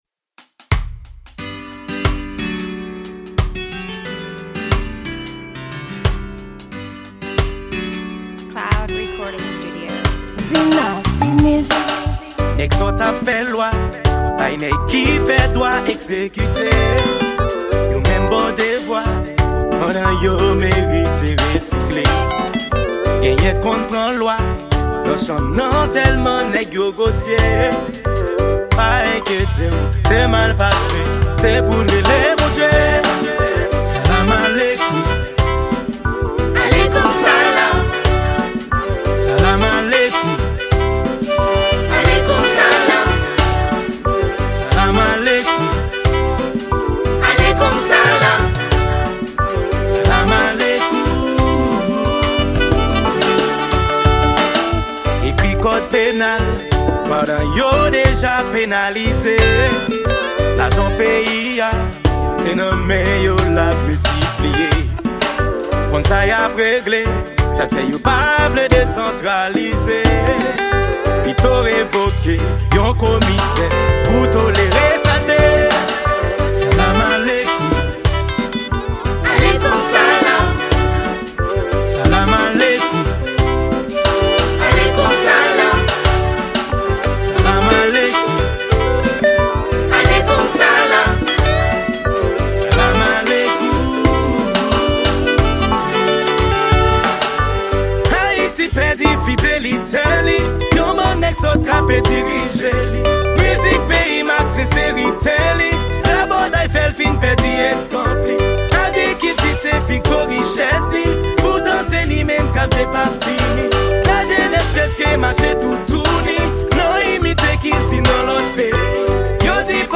Genre: Reggae.